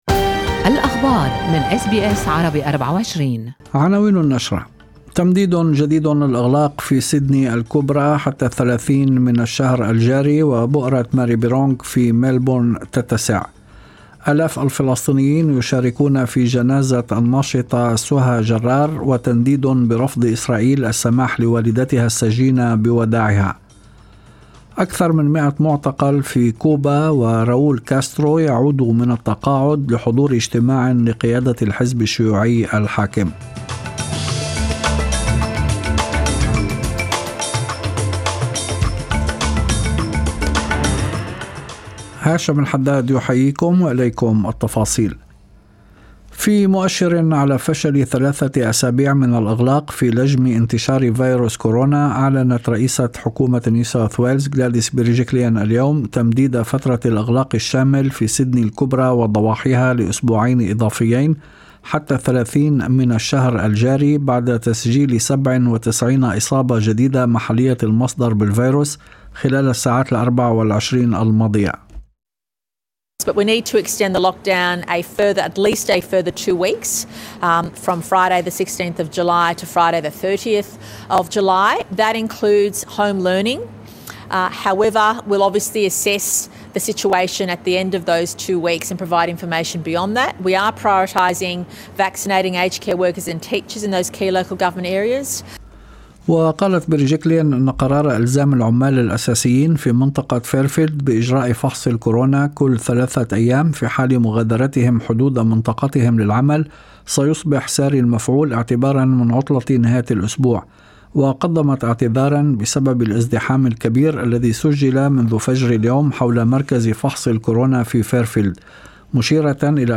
نشرة أخبار المساء 14/7/2021